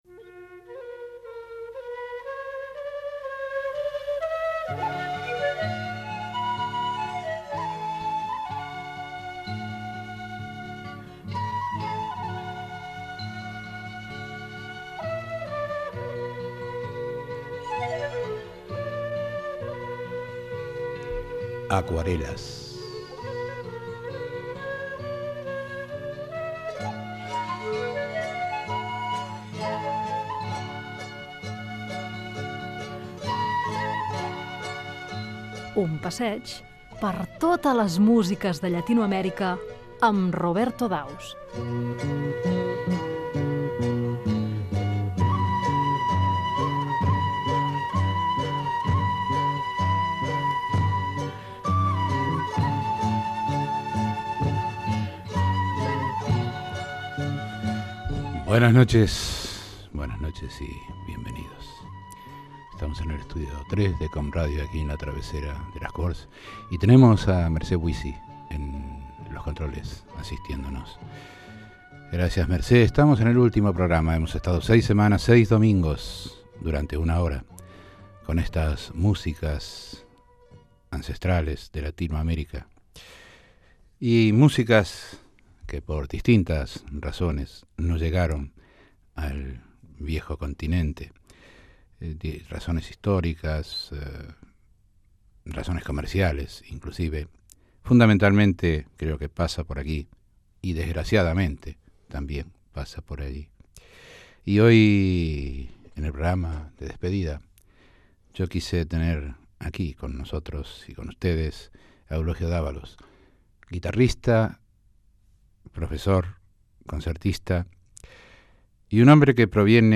Careta i presentació del darrer programa de la temporada d'estiu.
Musical